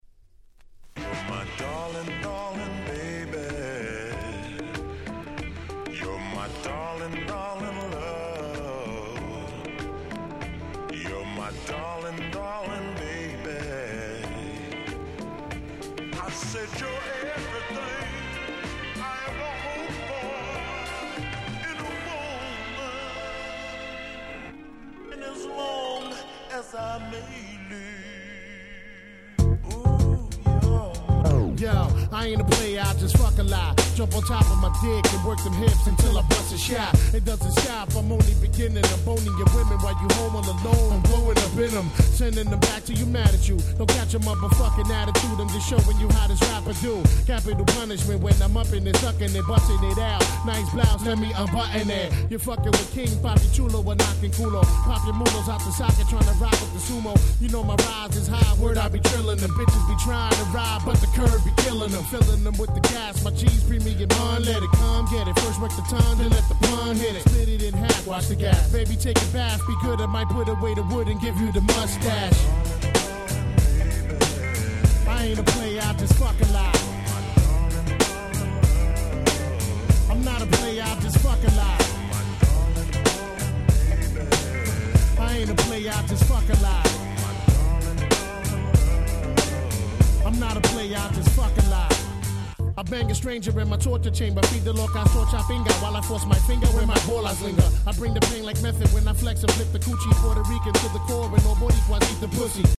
97' Big Hit Hip Hop !!!!